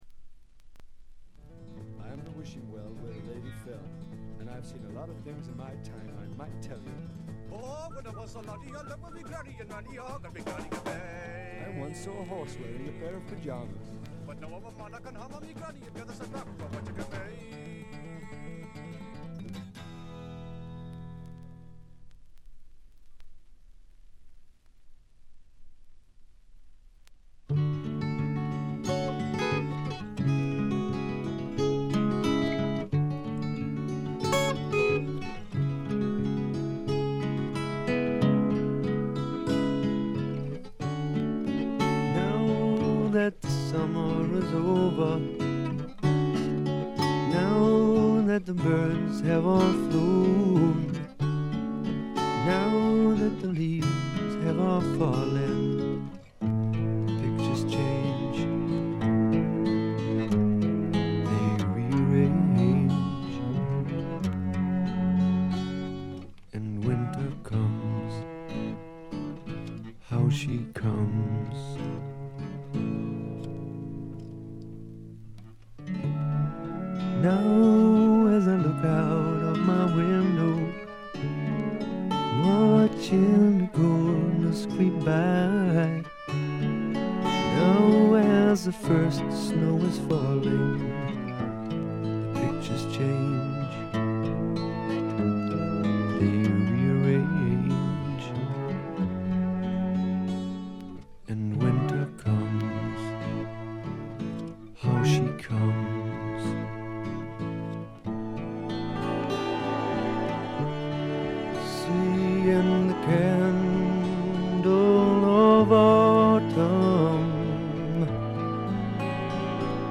ちょいと鼻にかかった味わい深いヴォーカルがまた最高です。
試聴曲は現品からの取り込み音源です。